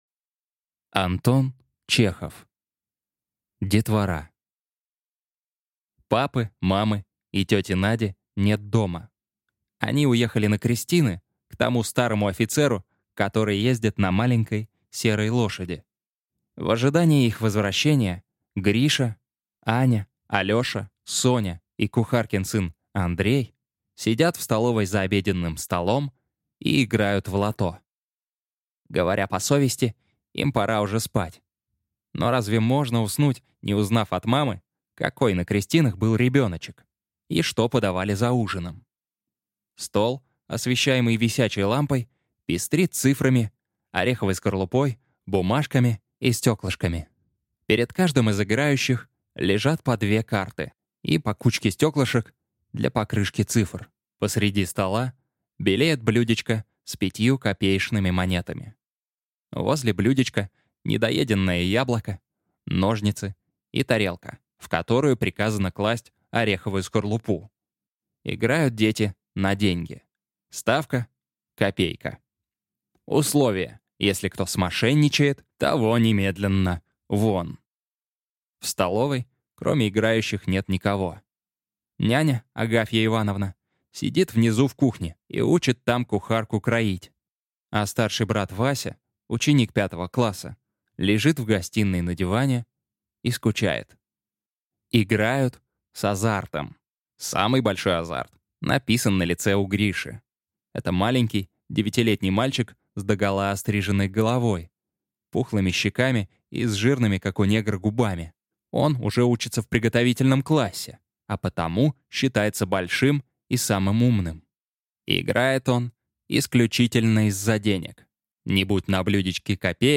Аудиокнига Детвора | Библиотека аудиокниг